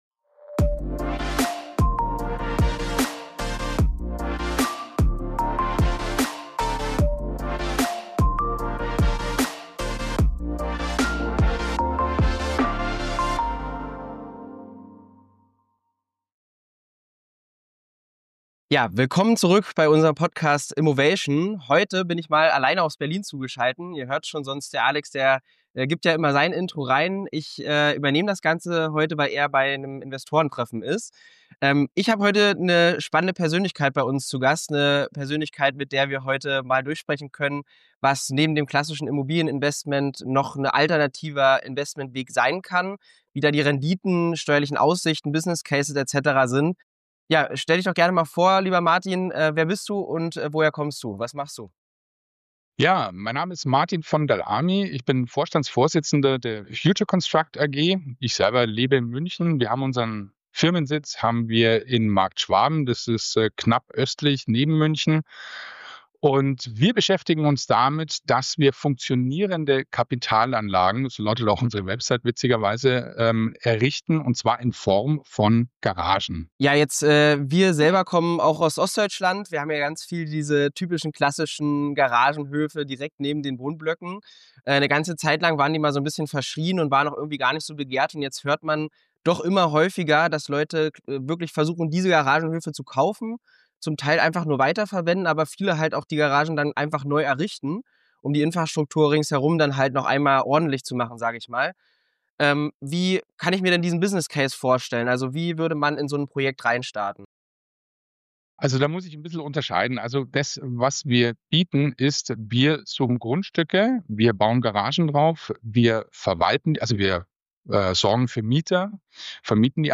Ein praxisnahes Gespräch für alle, die über den Tellerrand klassischer Wohnimmobilien hinausschauen möchten.